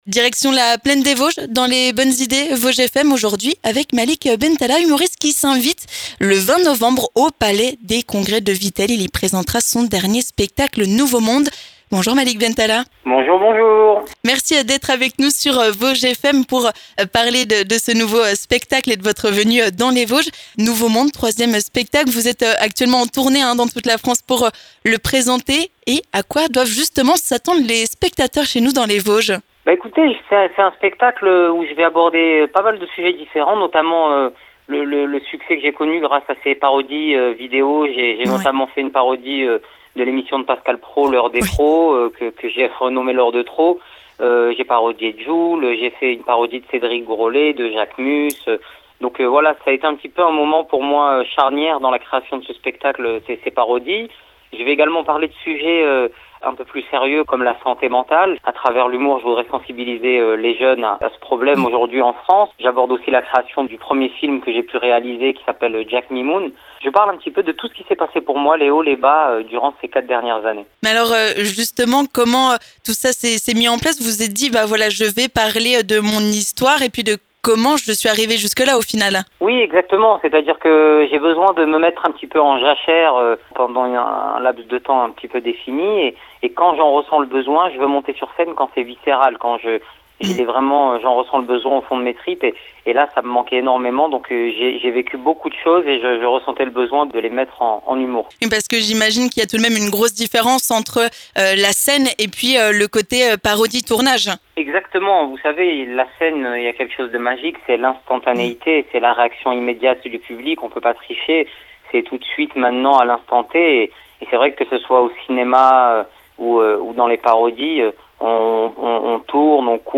Malik Bentalha est dans les Bonnes Idées Vosges FM pour nous parler de son dernier spectacle.*